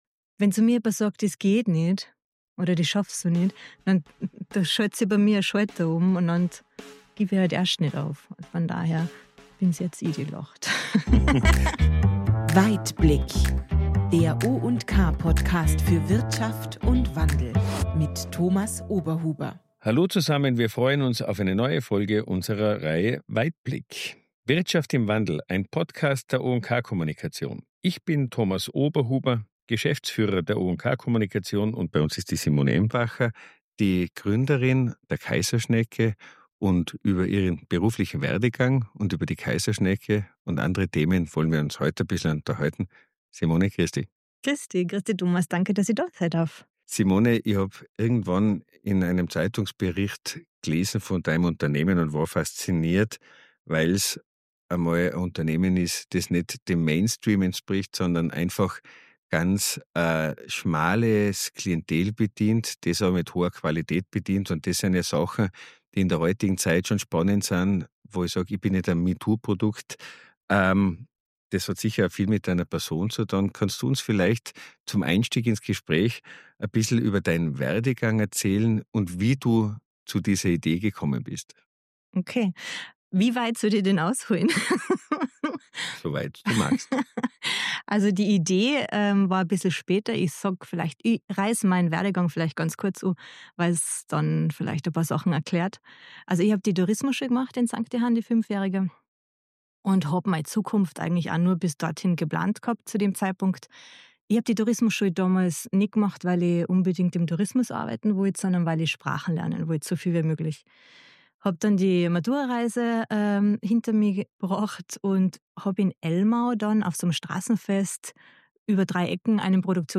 Es ist ein Gespräch über Female Entrepreneurship im ländlichen Raum, über nachhaltige Lebensmittelproduktion – und darüber, wie man aus einer außergewöhnlichen Idee eine funktionierende Landwirtschaftsform macht.